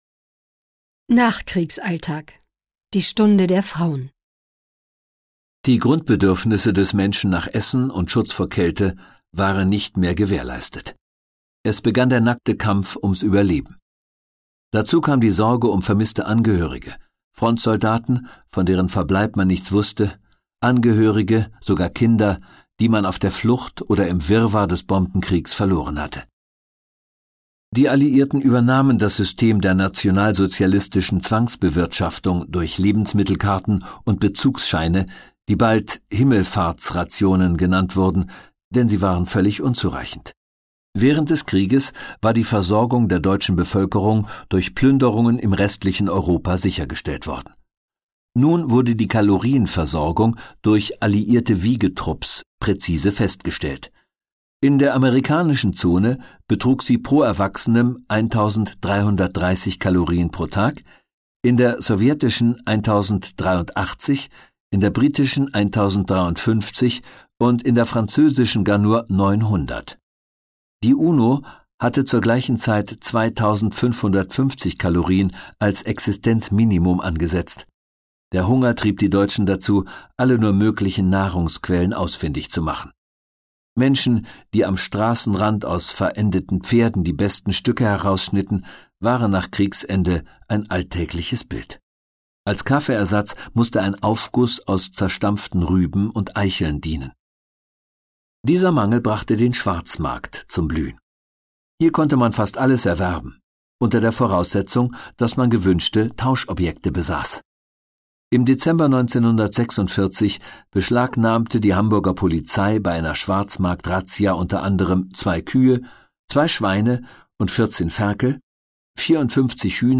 Schlagworte Alliierte • Audio-CD, Kassette / Sachbücher/Geschichte/Zeitgeschichte (1945 bis 1989) • Besatzungszonen • Besatzungszonen; Audio-CDs • Deutschland • Geschichte • Geschichtsdokumentation • Geschichtsdokumentationen (Audio-CDs) • Nachkriegsdeutschland • Nachkriegszeit • Nachkriegszeit; Audio-CDs • Nachkriegszeit (nach dem 2.